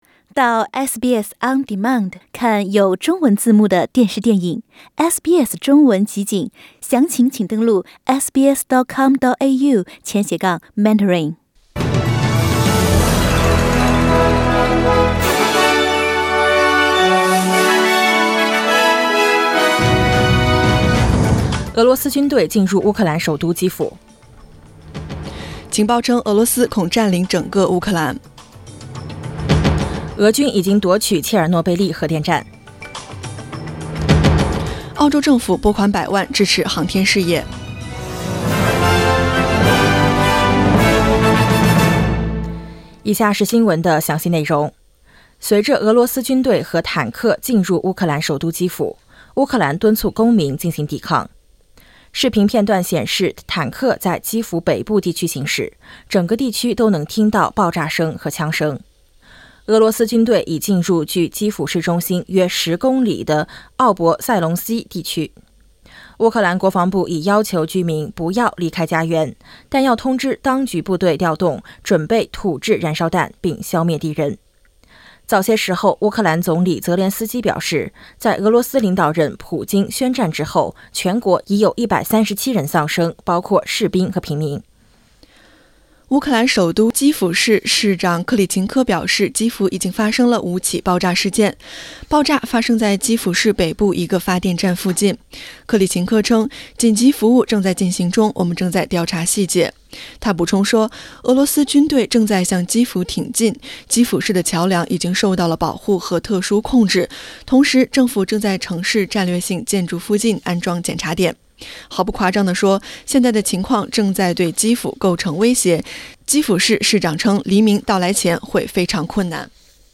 SBS早新闻（2022年2月26日）